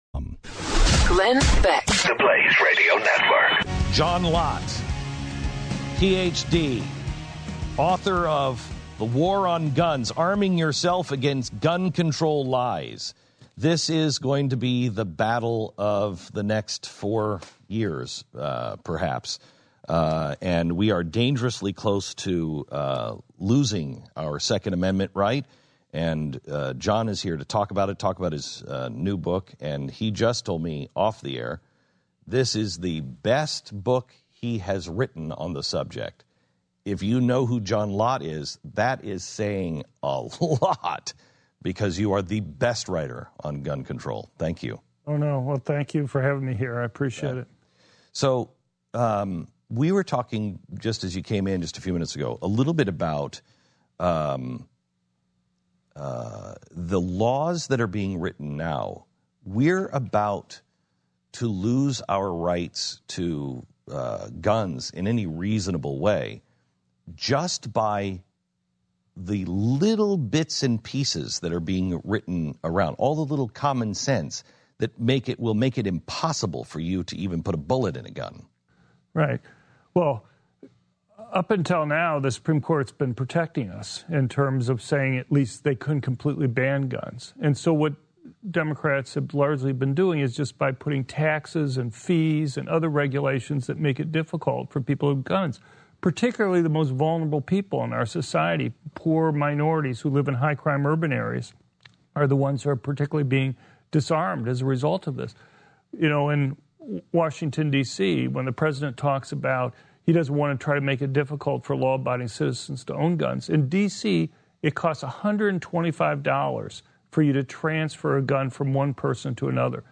John Lott, Jr., the man many believe to be the nation’s preeminent gun expert, joined Glenn on radio Thursday to discuss his new book, The War on Guns: Arming Yourself Against Gun Control Lies. According to Lott, the Supreme Court has protected our rights to the extent that they’ve not outrightly banned guns, but legislation has chinked away at the Second Amendment.